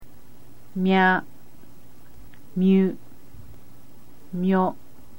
In order to make a sound equivalent to KYA rather than have a character for this sound, the character KI is used followed by a small YA.
mya.mp3